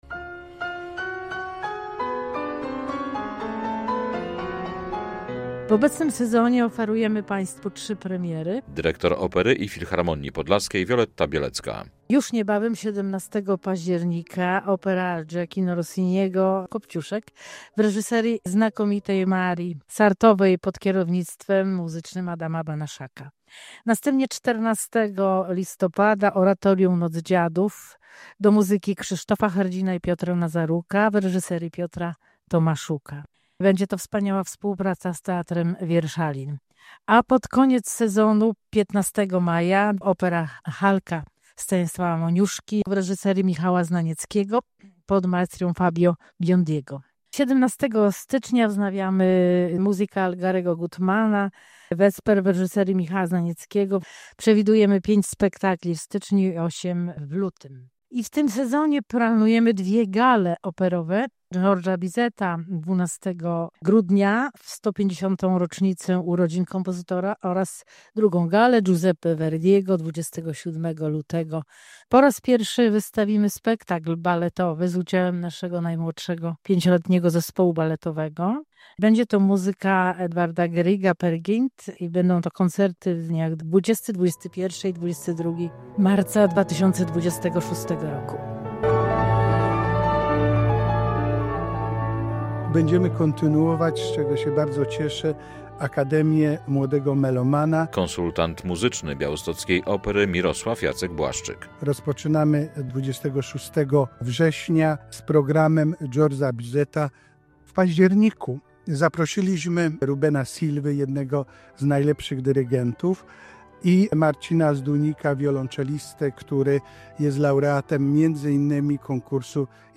Opera i Filharmonia Podlaska rozpoczęła sezon artystyczny - relacja